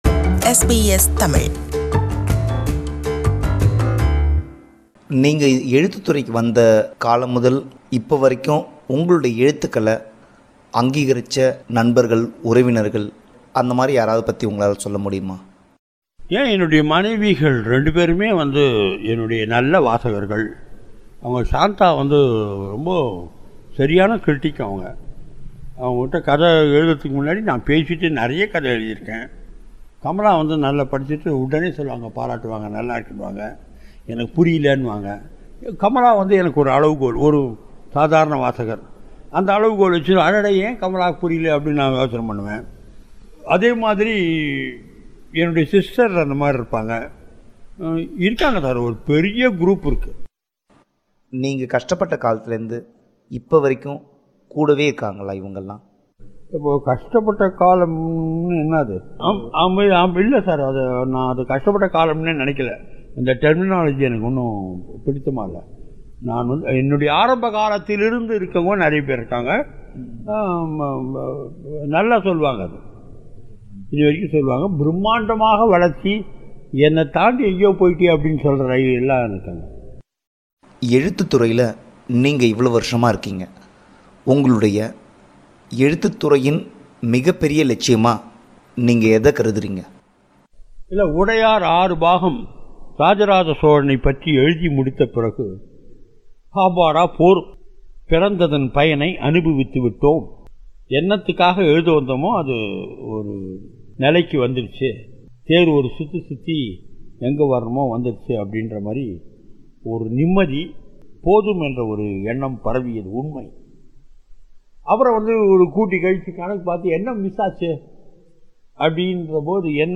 Interview with novelist Balakumaran – Part 2
It’s a rebroadcast of the interview – Part 2.